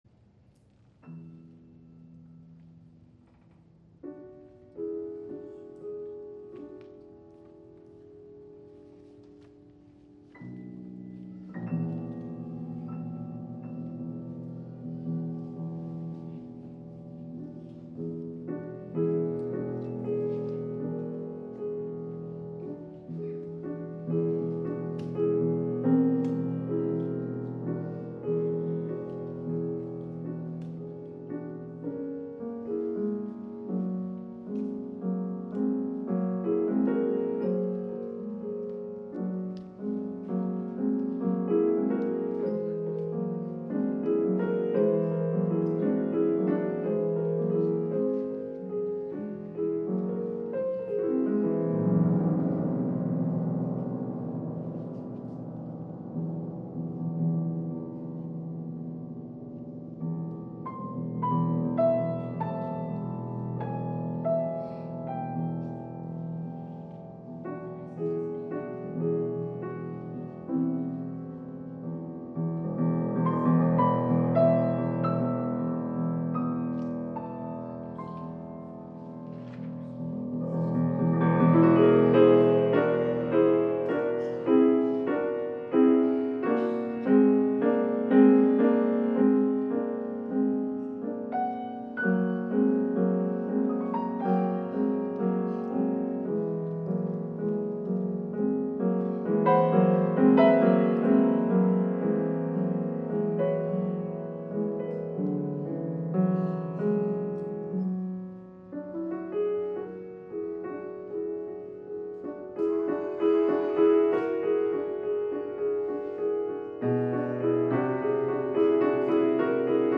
4-частная сюита для фортепиано:
Импрессионизм
Запись произведена на концерте по композиции